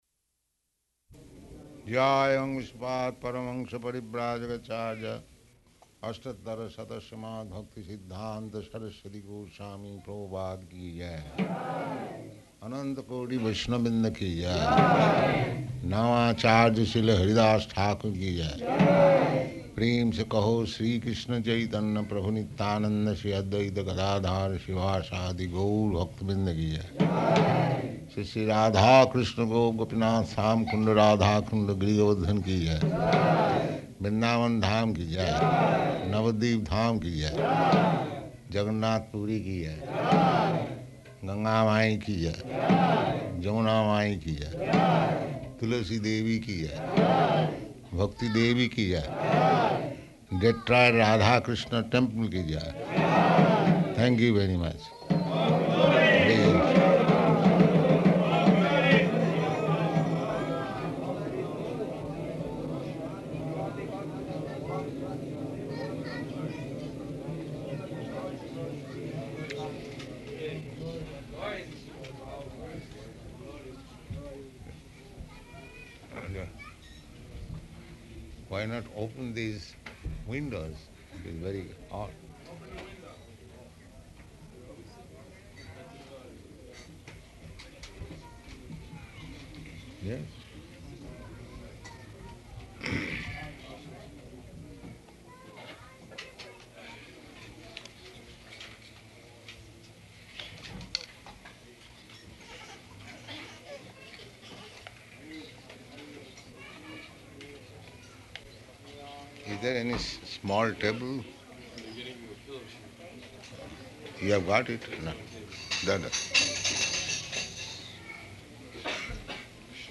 Lecture
Lecture --:-- --:-- Type: Lectures and Addresses Dated: July 16th 1971 Location: Detroit Audio file: 710716LE-DETROIT.mp3 Prabhupāda: [ prema-dhvani ] [devotees offer obeisances] Why not open these windows?